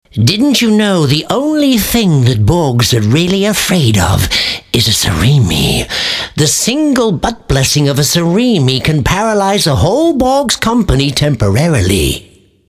english speaker, englischer sprecher, mittlere Stimme
Sprechprobe: Sonstiges (Muttersprache):
Warm voice, very flexible for animation and character work.